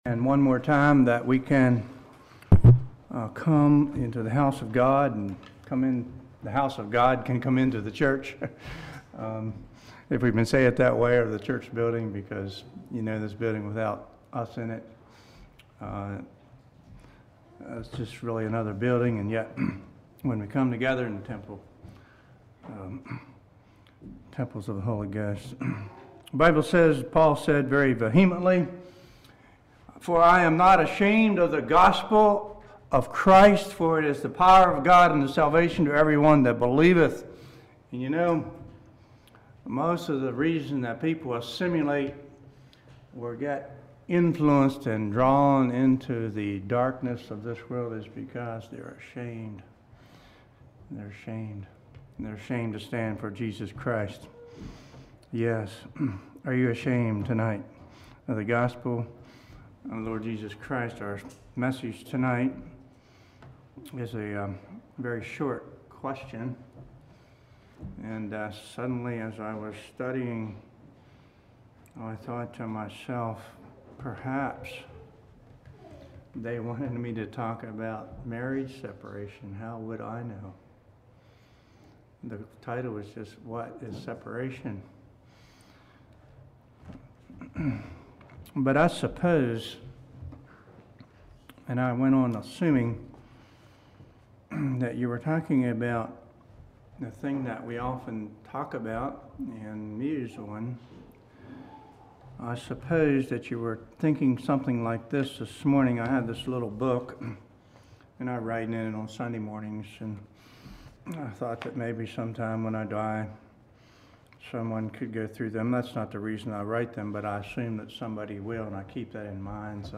Congregation: Providence Speaker